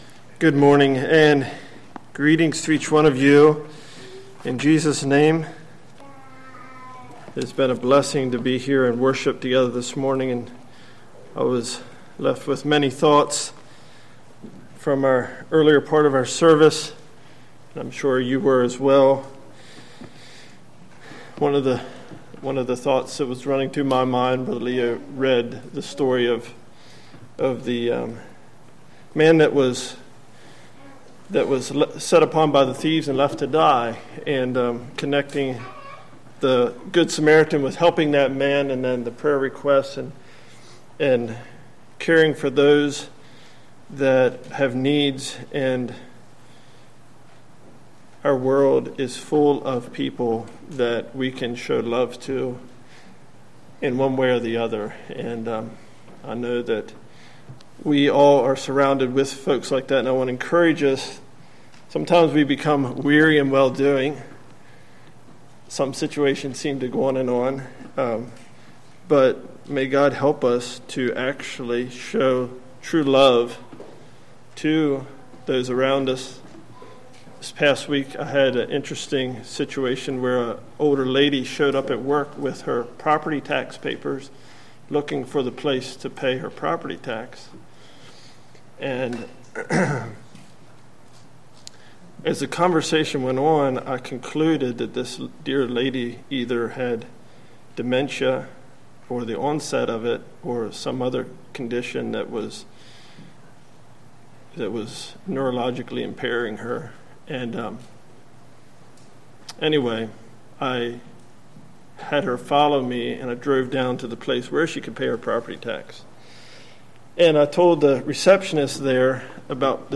Congregation: Life in Christ